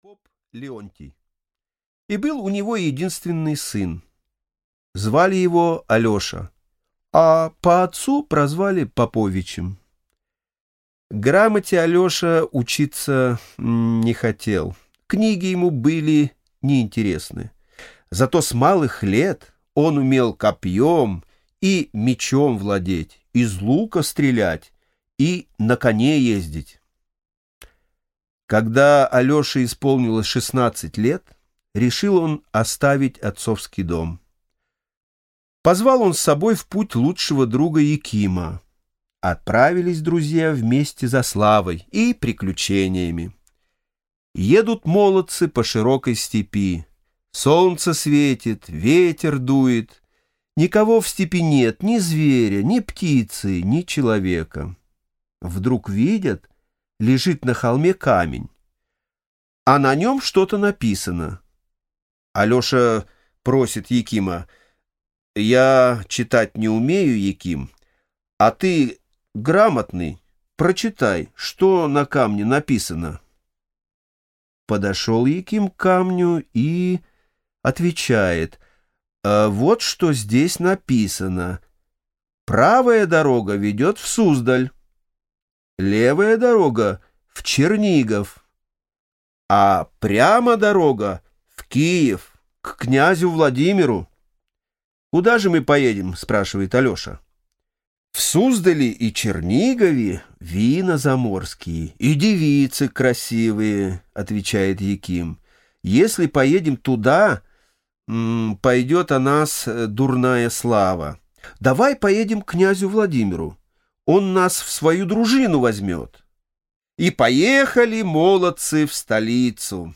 Audio kniha
Ukázka z knihy